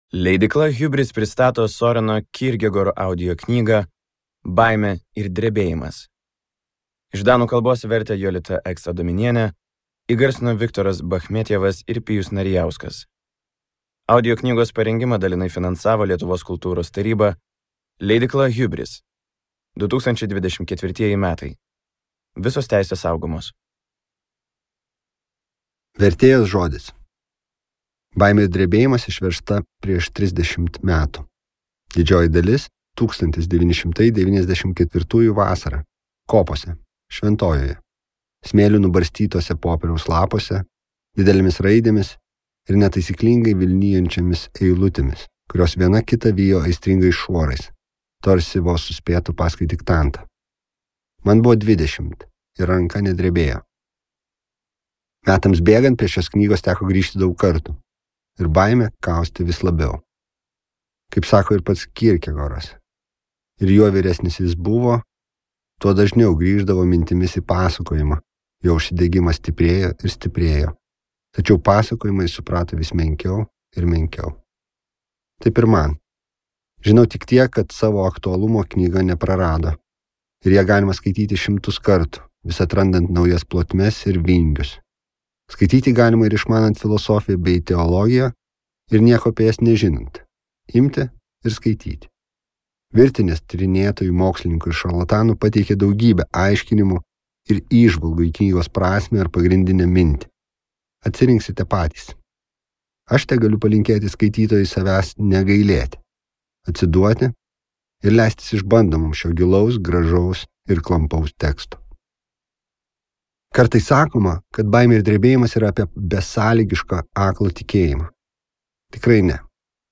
Søreno Kierkegaardo audioknyga „Baimė ir drebėjimas“ – filosofinis veikalas, nagrinėjantis tikėjimo paradoksus, Abraomo aukos istoriją ir egzistencinės atsakomybės klausimus.